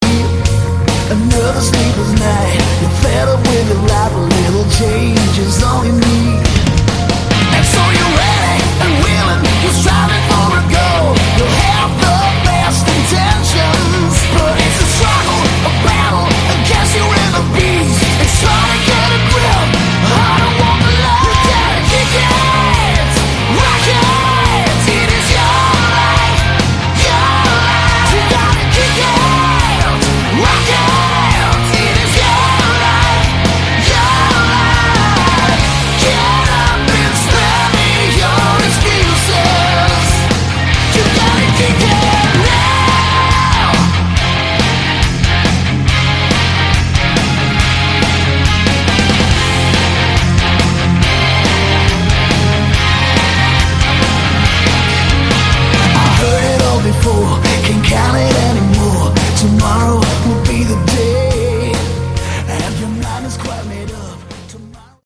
Category: Melodic Metal
guitars
vocals
bass
keyboards
drums